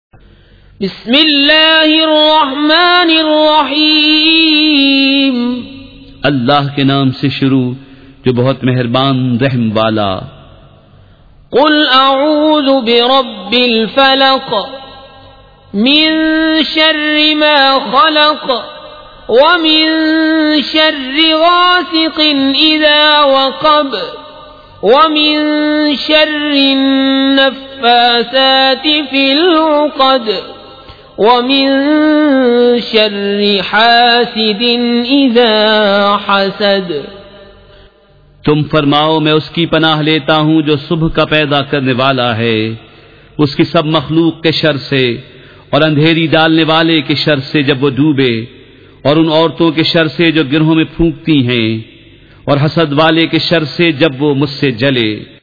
سورۃ الفلق مع ترجمہ کنزالایمان ZiaeTaiba Audio میڈیا کی معلومات نام سورۃ الفلق مع ترجمہ کنزالایمان موضوع تلاوت آواز دیگر زبان عربی کل نتائج 3036 قسم آڈیو ڈاؤن لوڈ MP 3 ڈاؤن لوڈ MP 4 متعلقہ تجویزوآراء